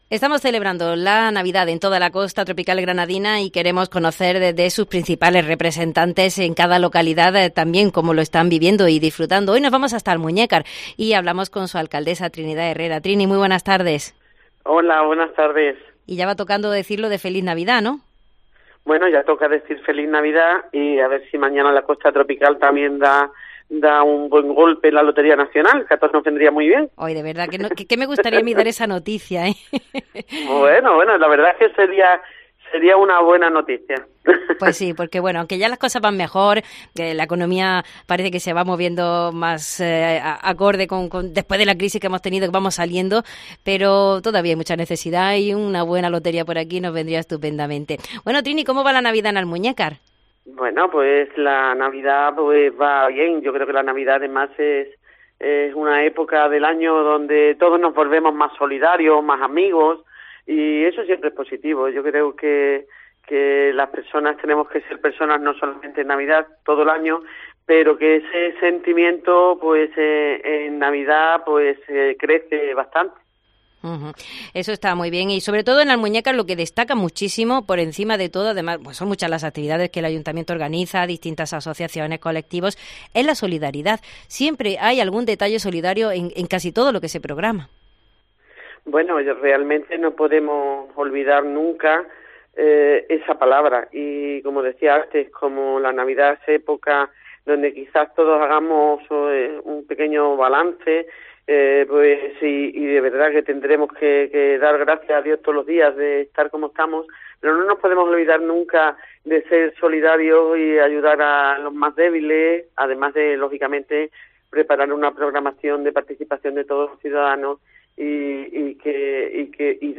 La alcaldesa de Almuñécar, Trinidad Herrera, nos habla de la celebración de la Navidad y las actividades más destacadas